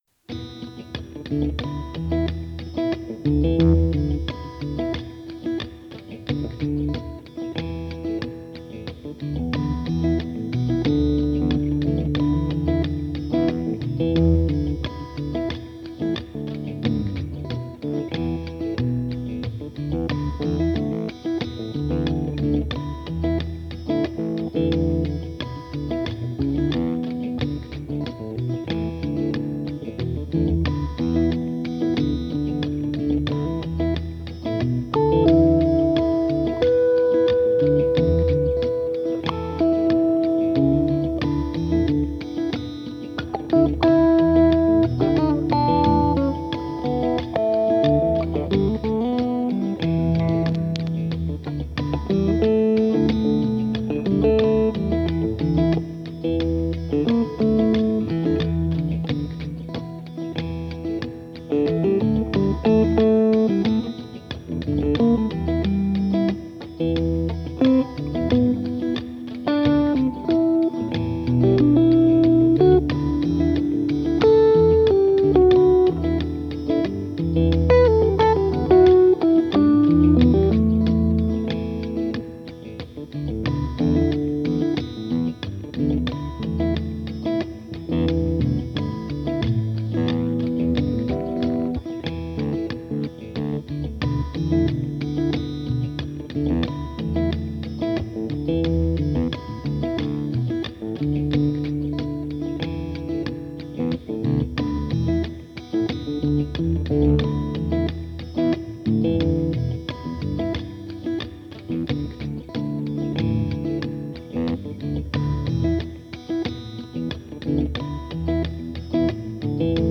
[curious about jon hassell’s psychogeography (zones of feeling) I listened to the first track of the album and immediately felt the strong need to create a somehow asymmetrical, pulsative floating loop with occasional bass dabs]